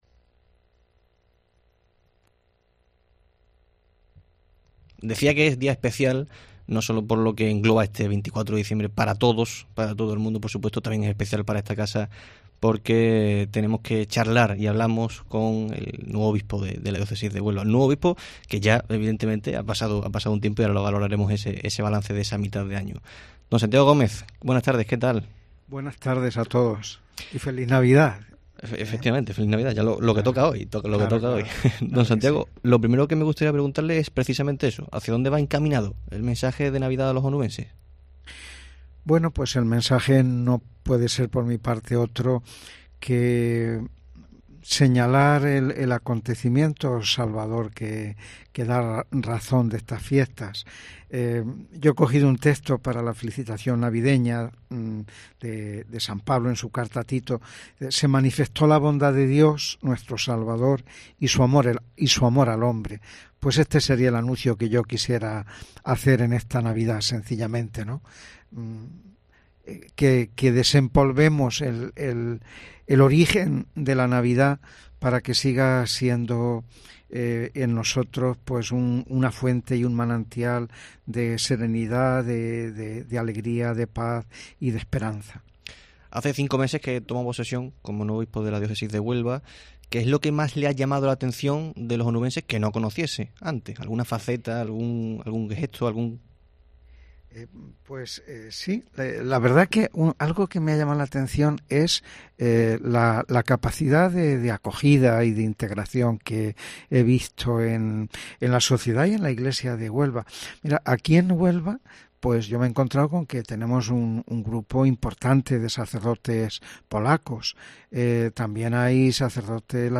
AUDIO: En el Herrera en COPE Huelva de este jueves 24 de diciembre, especial de Nochebuena, hablamos con el Obispo de Huelva, Santiago Gómez, que...